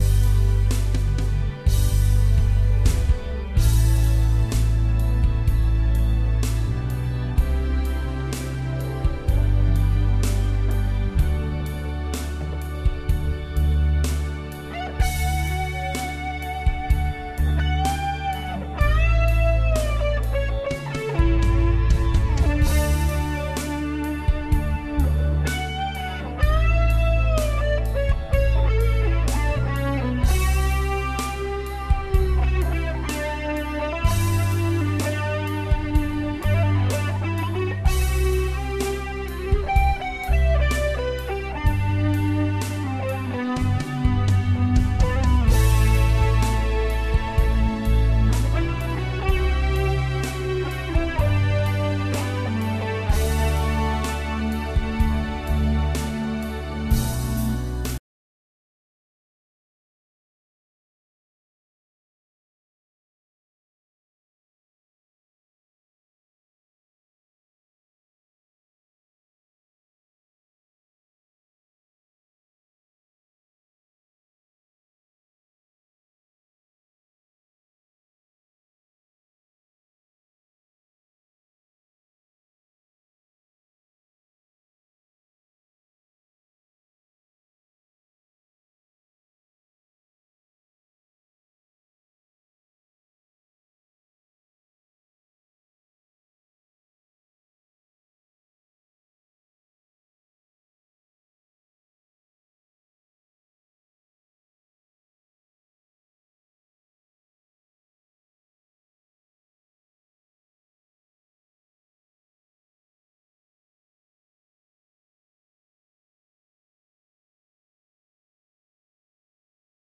- A bit of reverb added on the Boss recorder